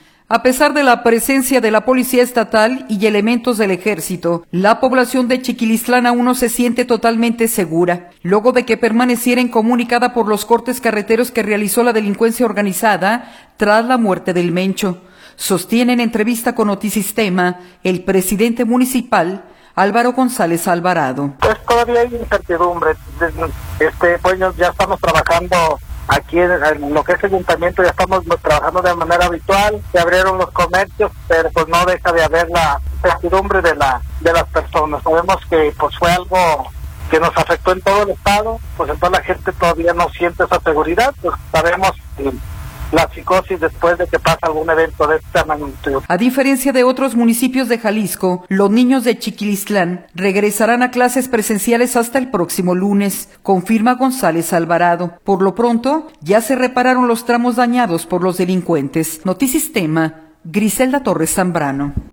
A pesar de la presencia de la policía estatal y elementos del ejército, la población de Chiquilistlán aún no se siente totalmente segura, luego de que permaneciera incomunicada por los cortes carreteros que realizó la delincuencia organizada tras la muerte de “El Mencho”, sostiene en entrevista con Notisistema, el presidente municipal, Álvaro González Alvarado.